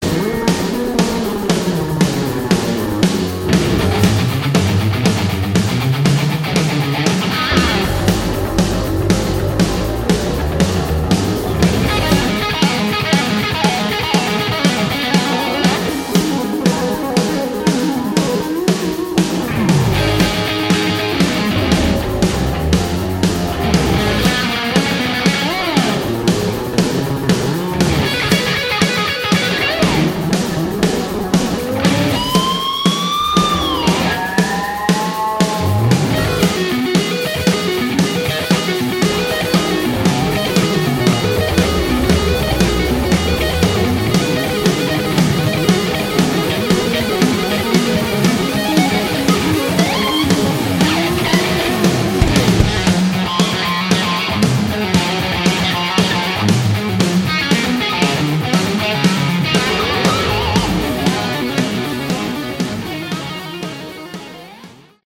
Category: Melodic Hard Rock
vocals, guitar
bass, vocals
drums, vocals